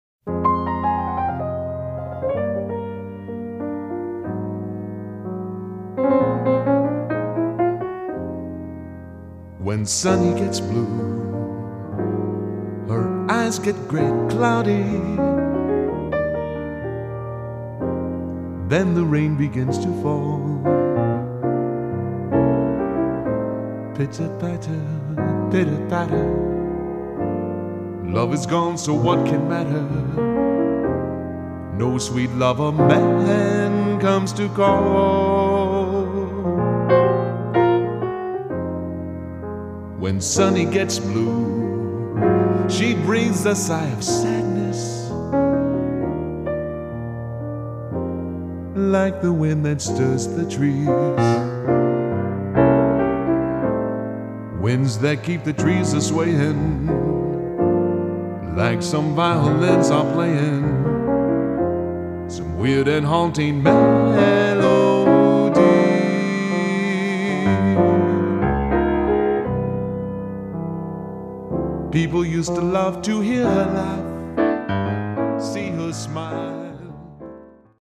Piano / Keyboard with Vocals:
Jazz Ballad